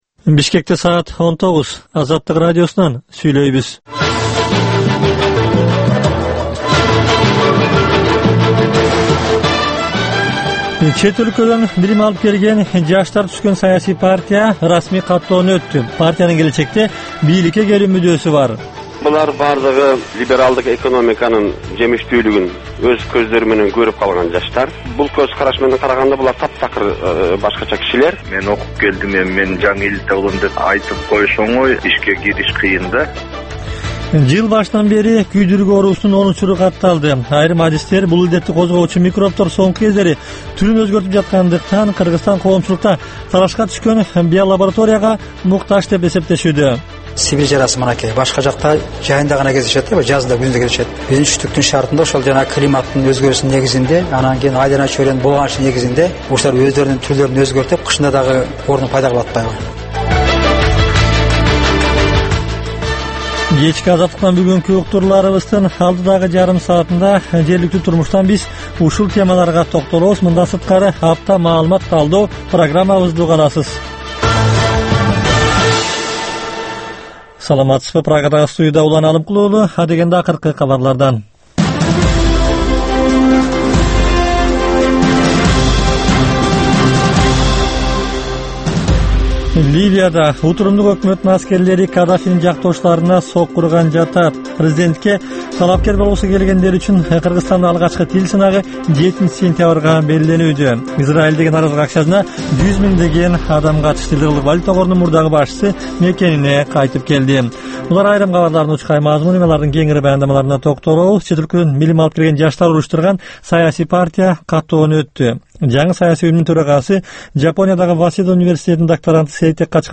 Кечки 7деги кабарлар